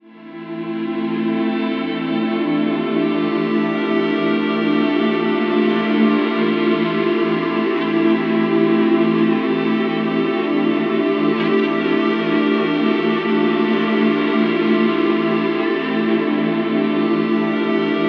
WEEPING 3 -R.wav